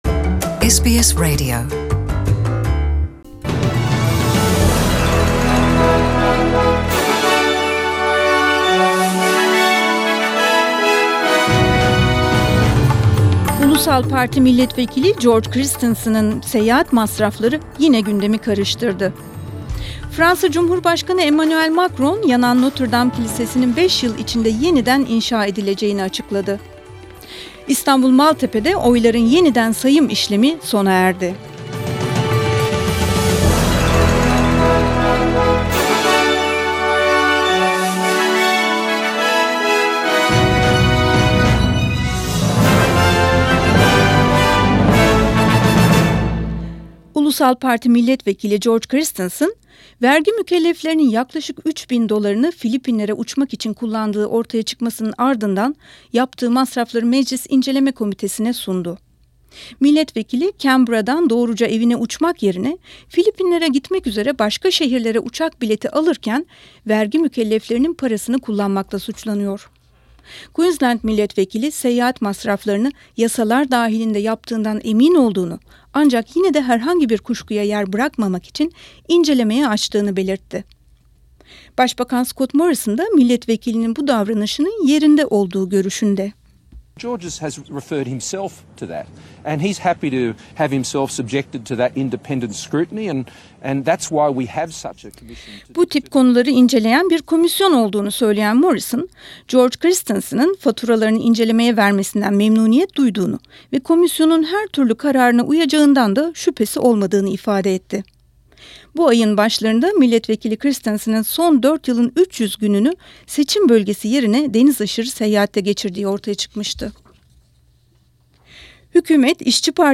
SBS Turkish News APRIL 17, 2019